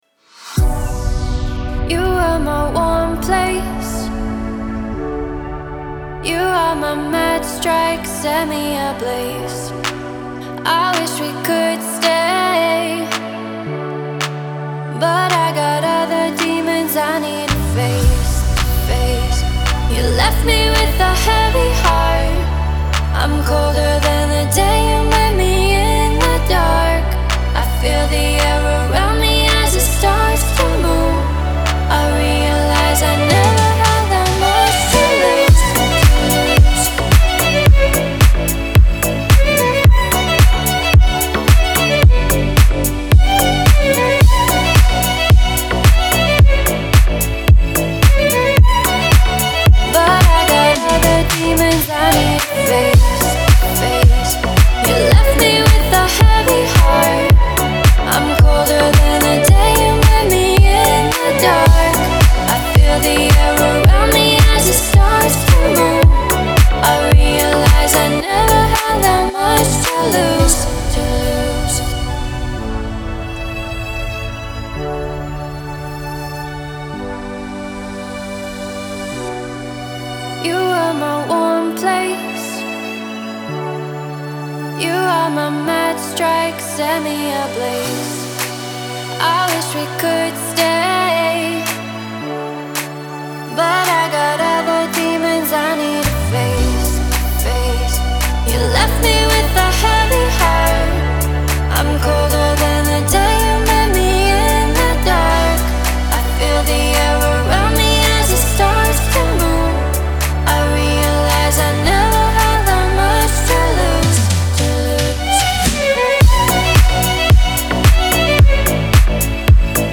энергичная электронная композиция